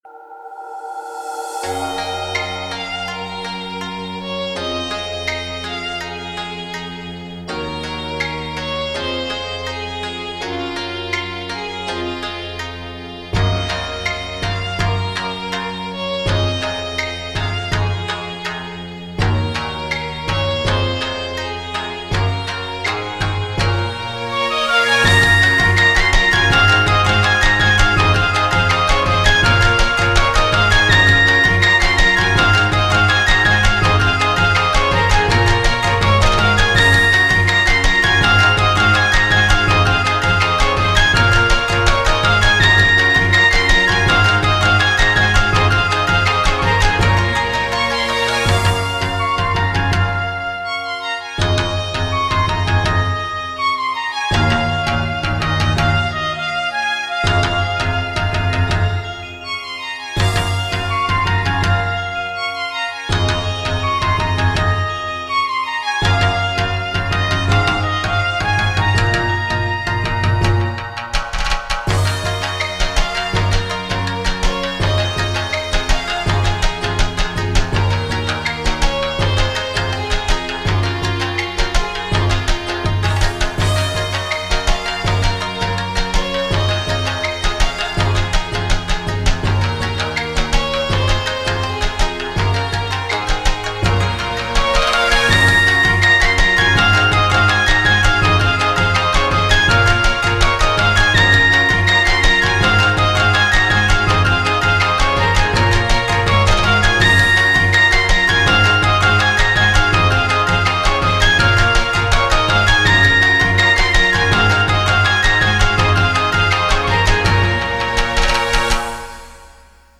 BPM82
Audio QualityPerfect (High Quality)
Genre: NEW AGE.